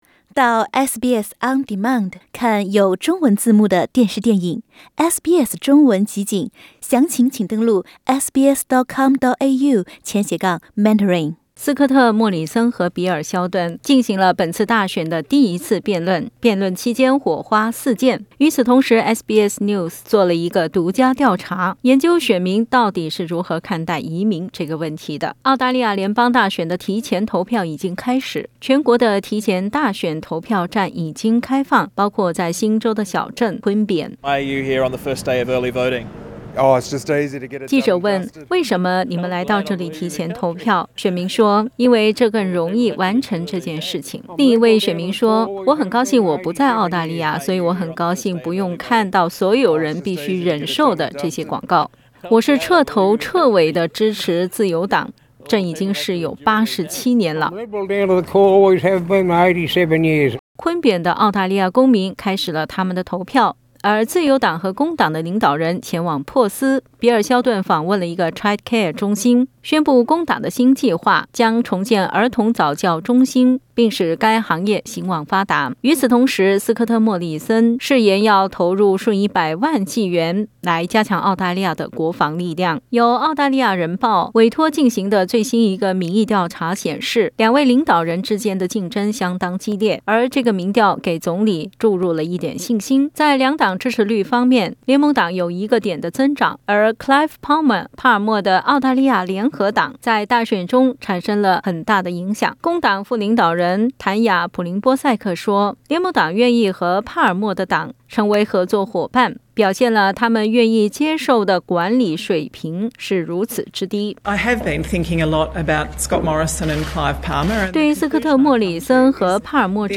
在新州的一个小镇，SBS记者采访了几位参加提前大选的选民。